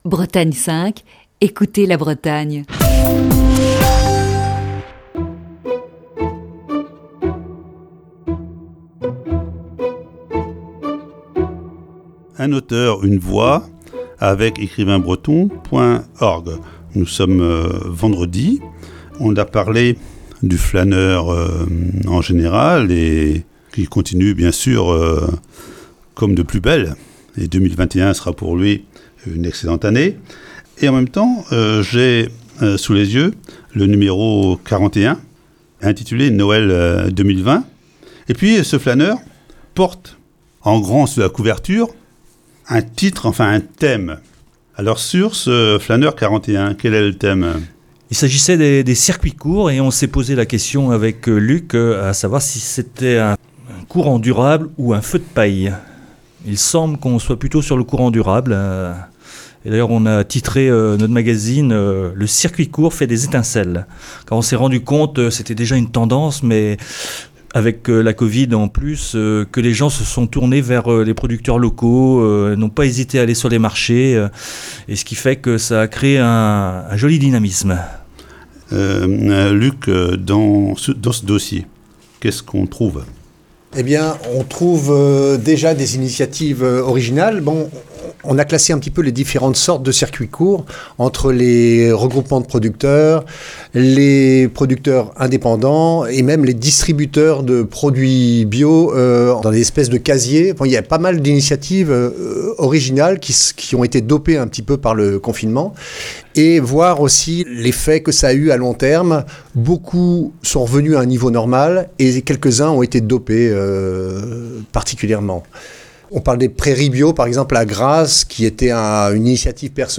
Voici ce vendredi, la cinquième et dernière partie de cette série d'entretiens.